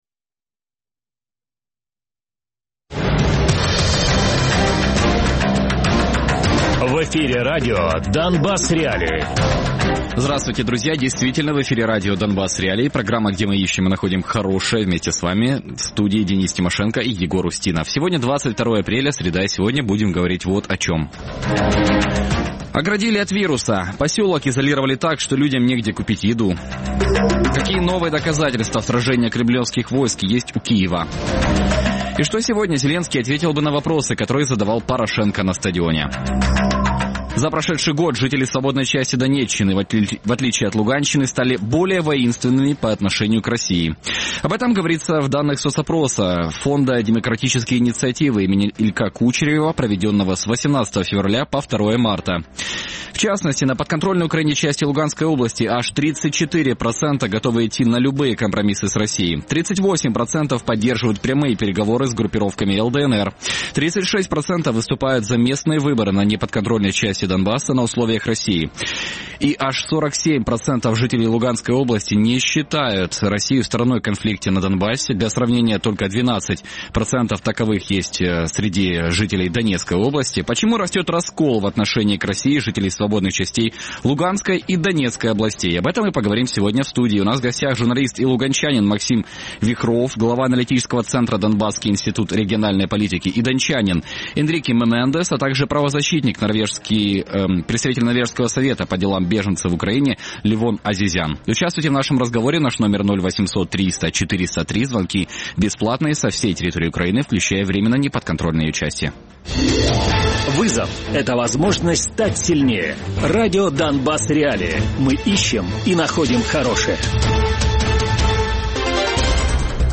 Гості студії